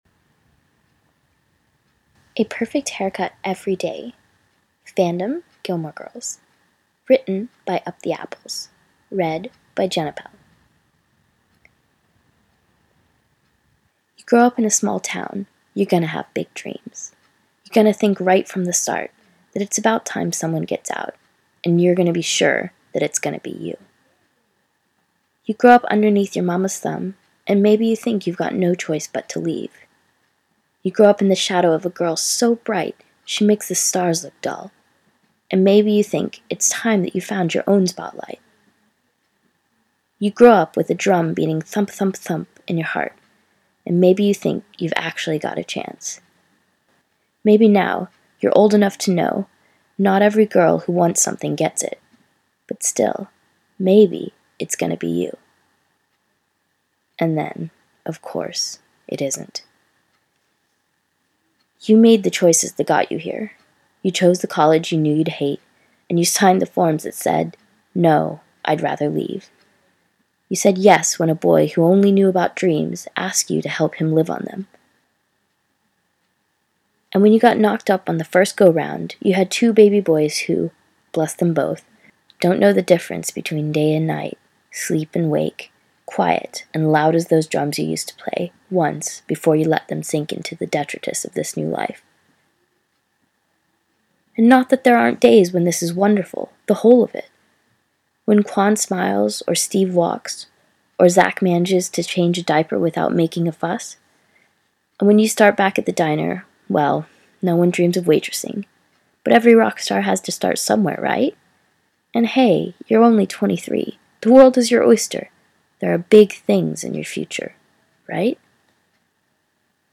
Podfic: 7 shorts for the "Awesome Ladies Ficathon"